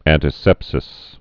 (ăntĭ-sĕpsĭs)